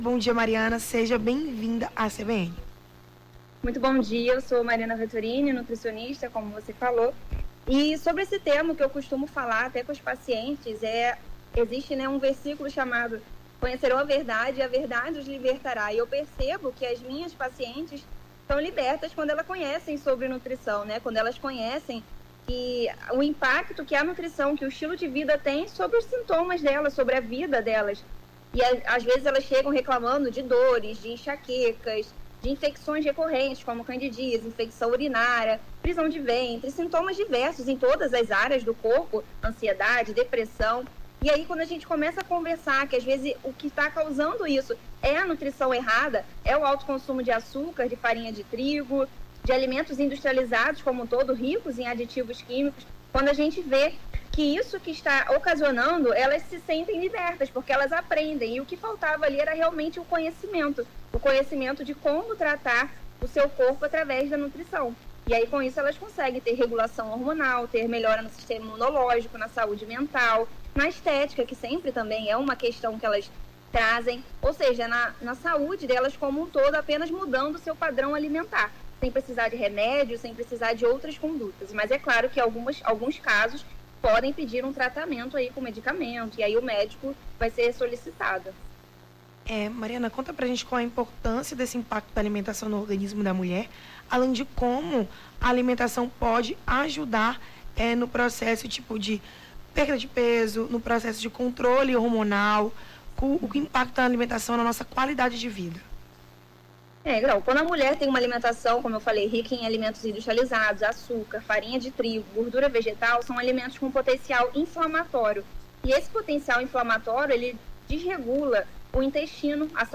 Nome do Artista - CENSURA - ENTREVISTA (NUTRIÇÃO MULHER) 10-03-23.mp3